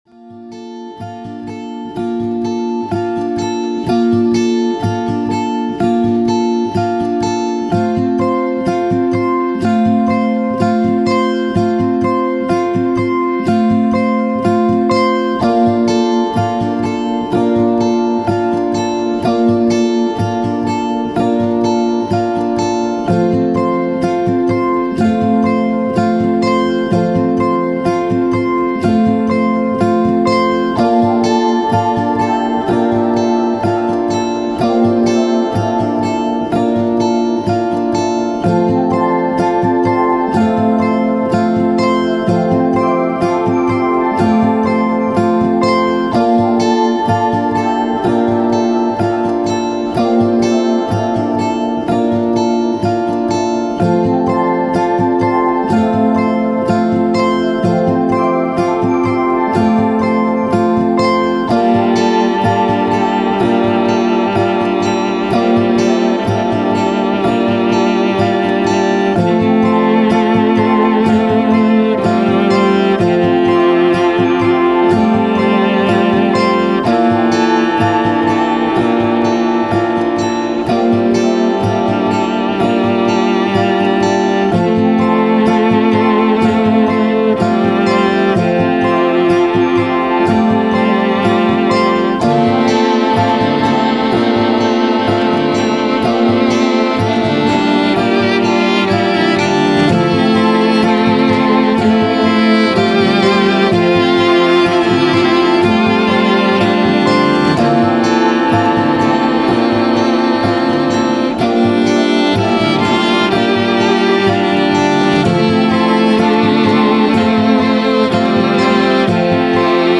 Ambient, Downtempo, Soundtrack, Acoustic, Strings, Story